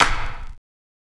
CLAP_WHISTLE.wav